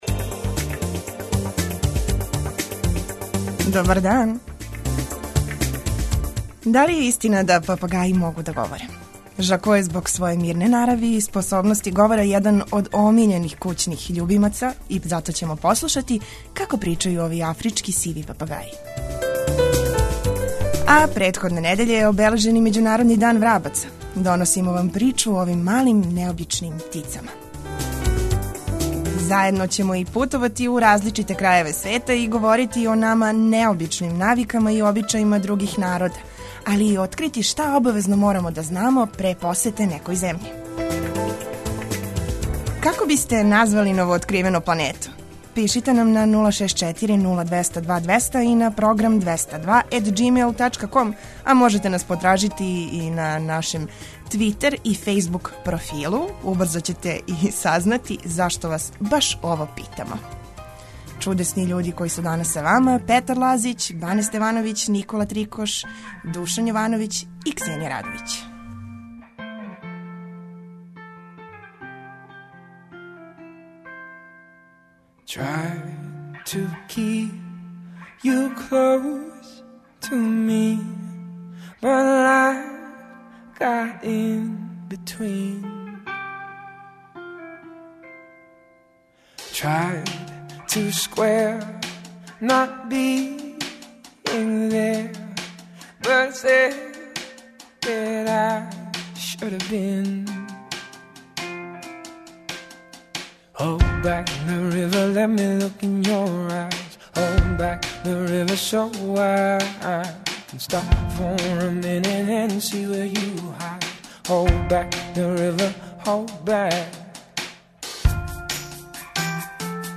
Послушаћeмо власника овог афричког сивог папагаjа како je њeгов љубимац научио да прича и како сe споразумeва са укућанима.